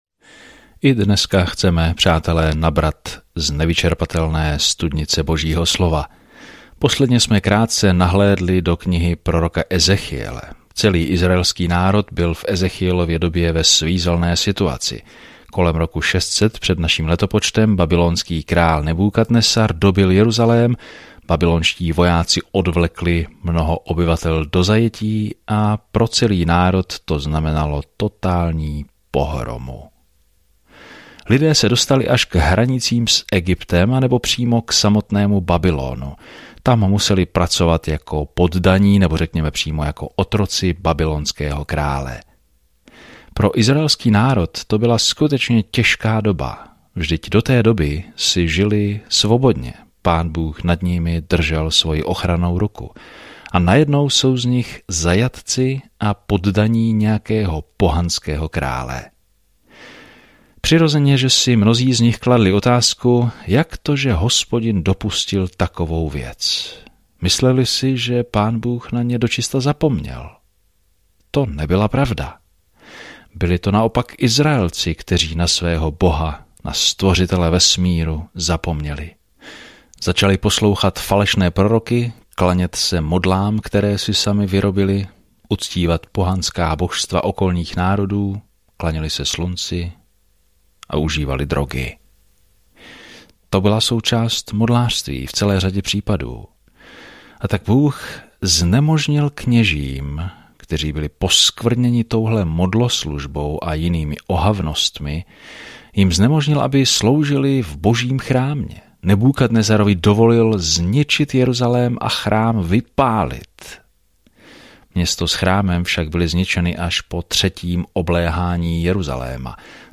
Písmo Ezechiel 1:15-28 Ezechiel 2 Ezechiel 3:1-9 Den 1 Začít tento plán Den 3 O tomto plánu Lidé nechtěli naslouchat Ezechielovým varovným slovům, aby se vrátili k Bohu, a tak místo toho předvedl apokalyptická podobenství a to probodlo srdce lidí. Denně procházejte Ezechielem a poslouchejte audiostudii a čtěte vybrané verše z Božího slova.